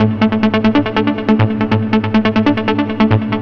TSNRG2 Lead 023.wav